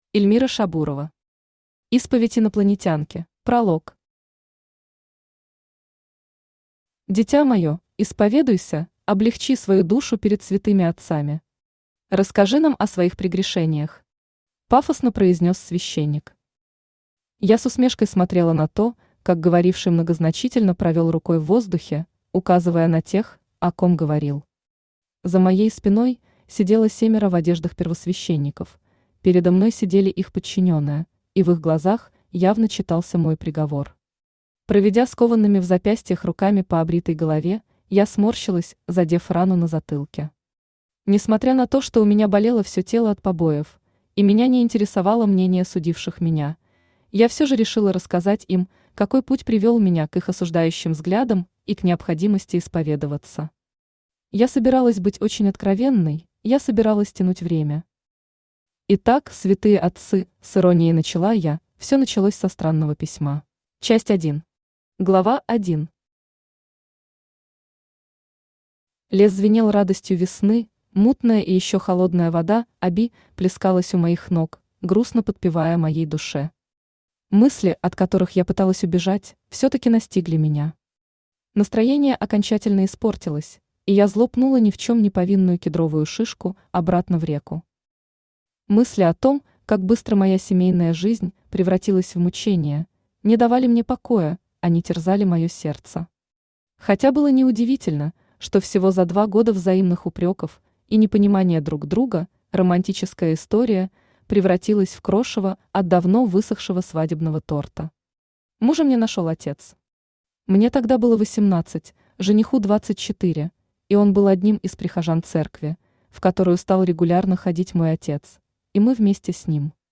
Аудиокнига Исповедь инопланетянки | Библиотека аудиокниг
Aудиокнига Исповедь инопланетянки Автор Эльмира Шабурова Читает аудиокнигу Авточтец ЛитРес.